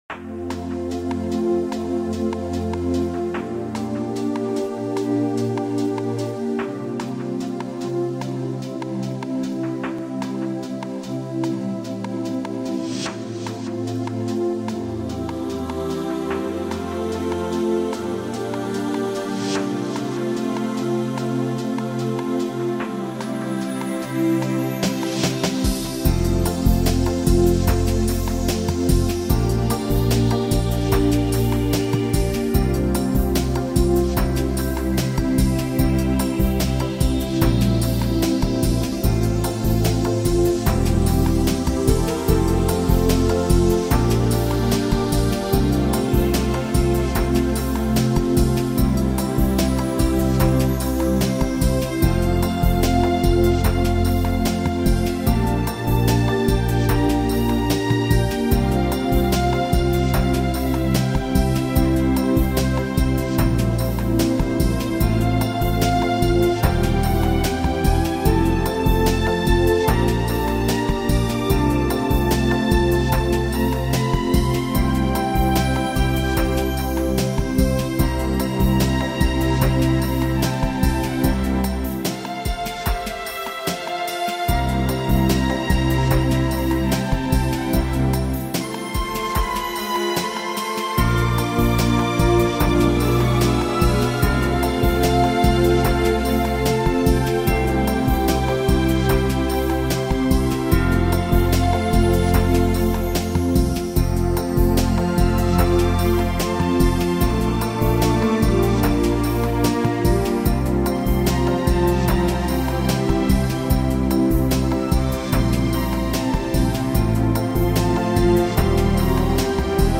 경음악